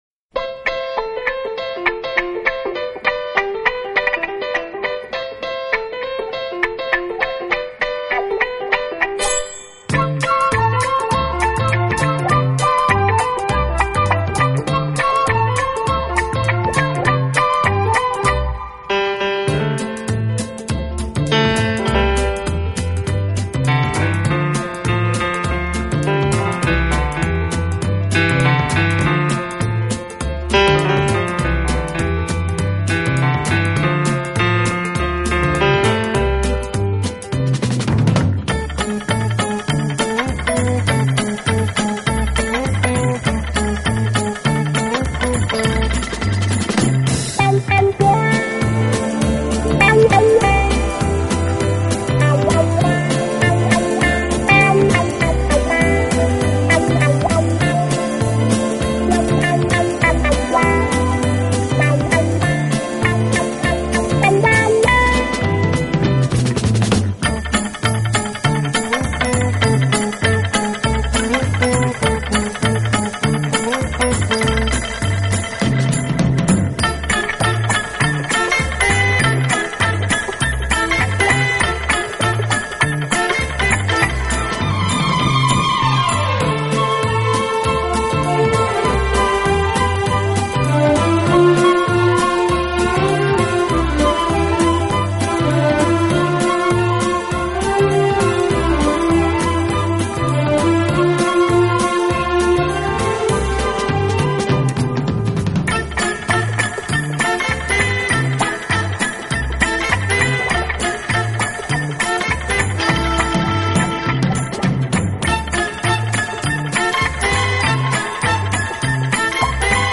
【轻音乐】